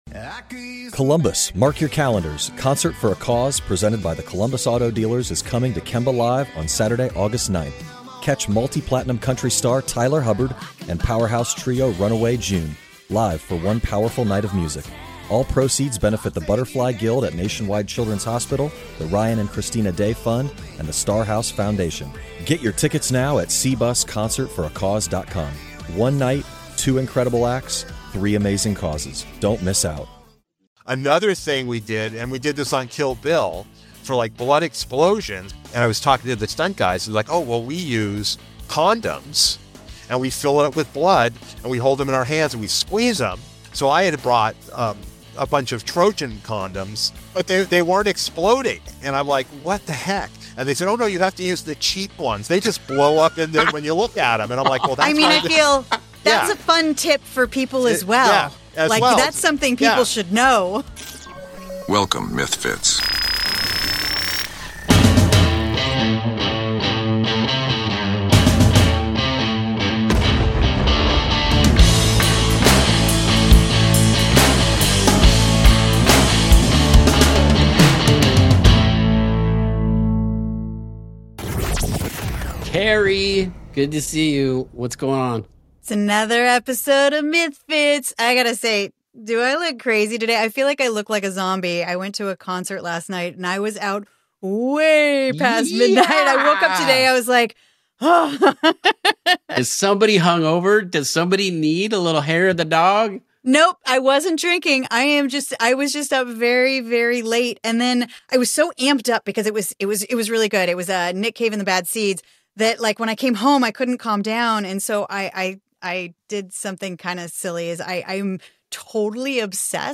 Special Effects Makeup: A Conversation with Howard Burger